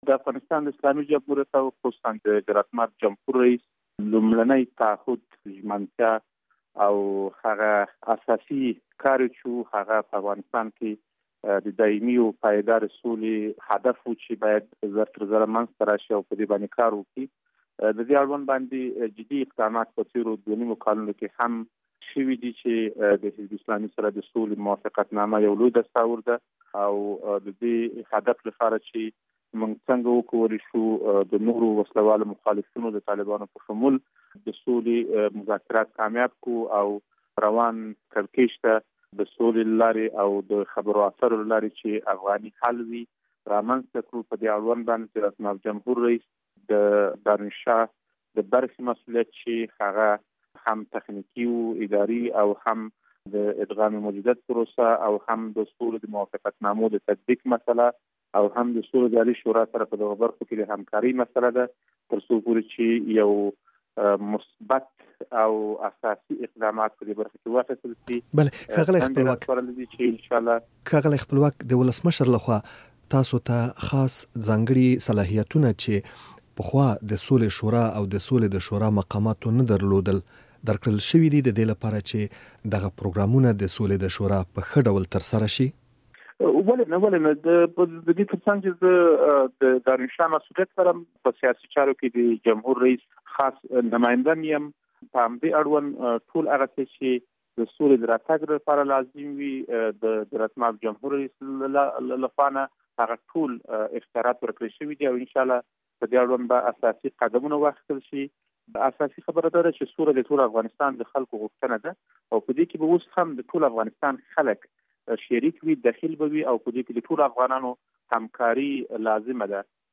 له اکرم خپلواک سره مرکه.